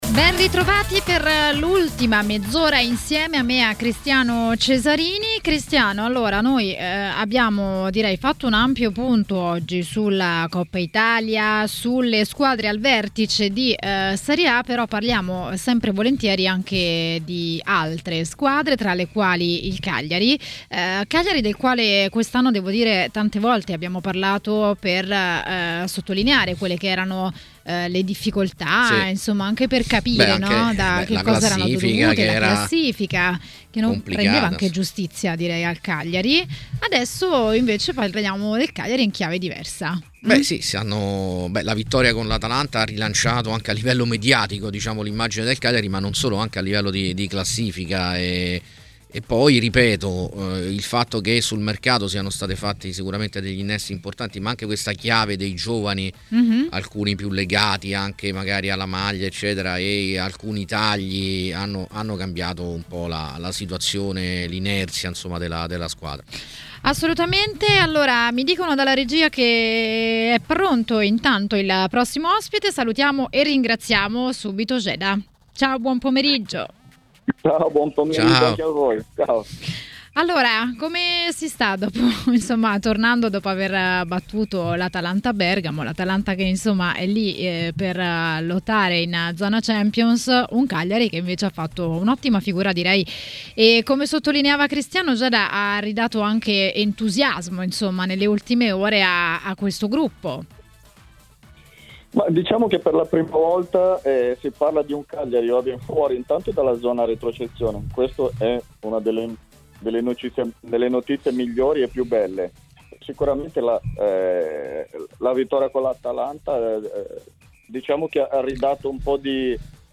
A parlare di Cagliari a TMW Radio, durante Maracanà, è stato l'ex calciatore Jeda.